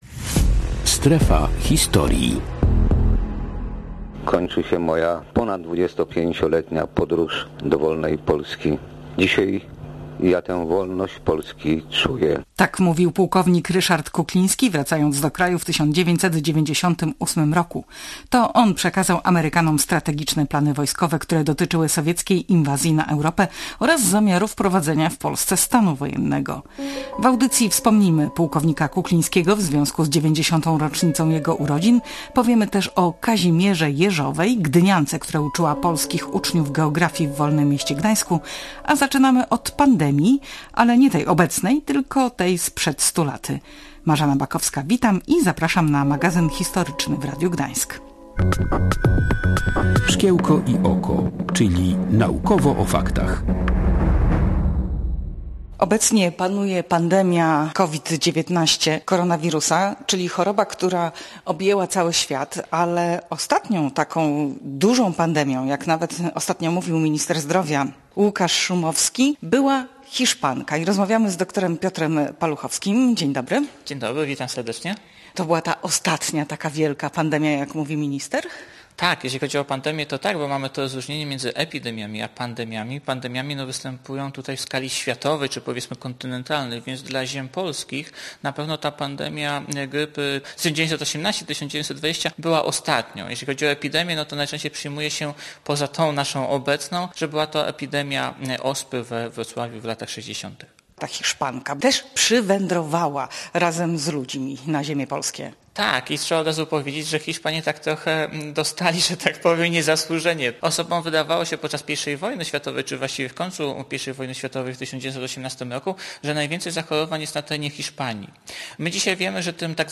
Przypominamy archiwalne wypowiedzi Ryszarda Kuklińskiego na ten temat.